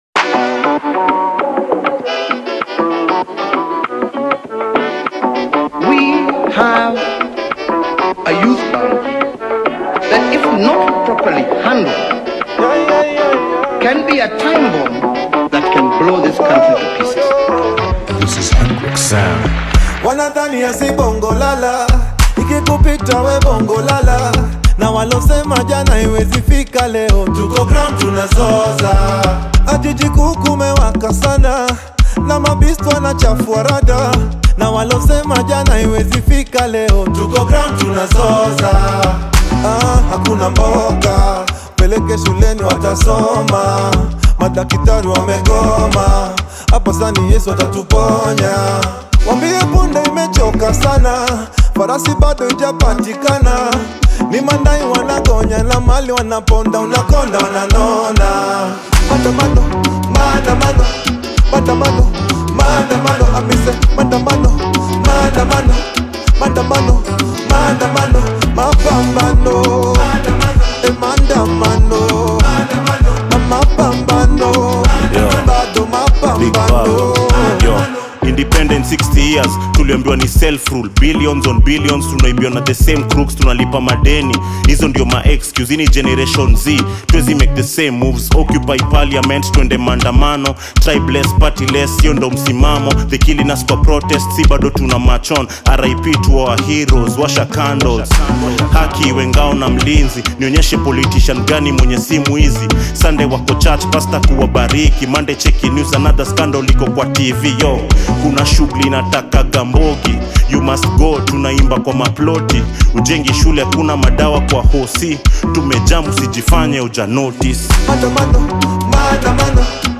a rising star in the Kenyan hip-hop scene.
smooth vocals